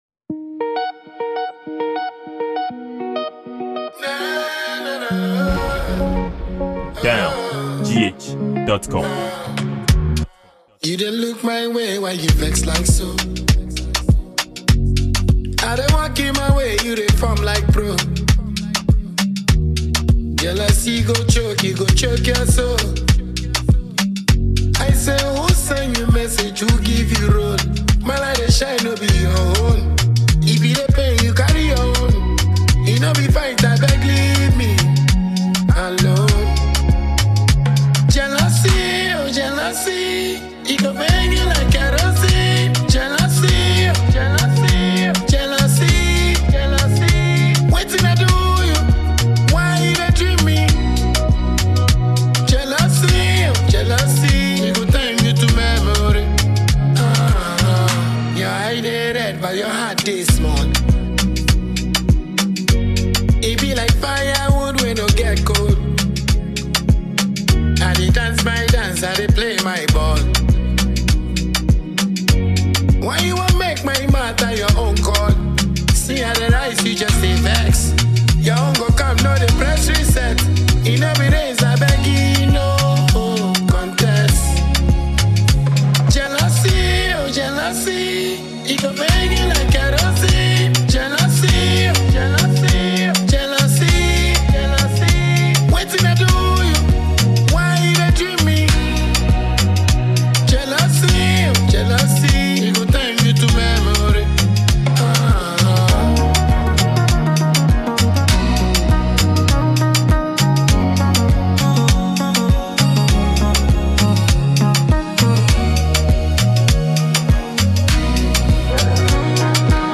a Ghanaian dancehall musician and songwriter.